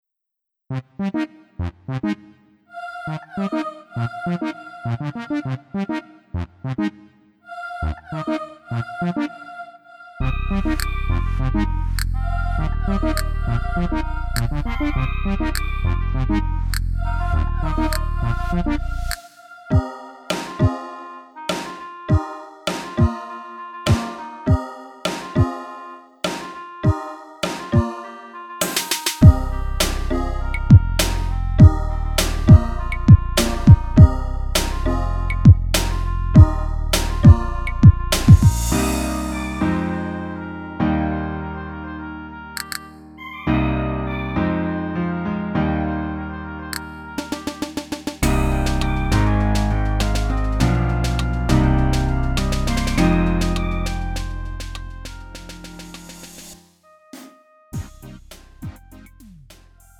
음정 -1키 3:25
장르 가요 구분 Lite MR
Lite MR은 저렴한 가격에 간단한 연습이나 취미용으로 활용할 수 있는 가벼운 반주입니다.